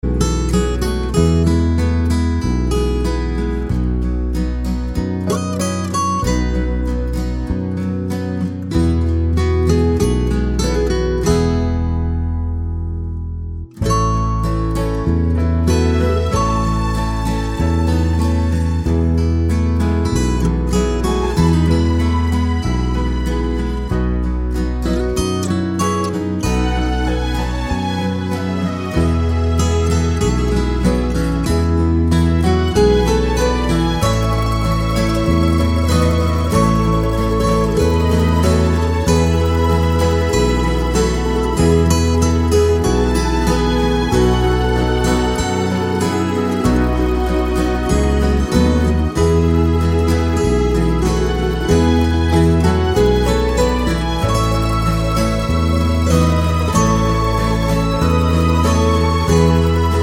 Irish